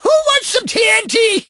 tnt_guy_start_vo_03.ogg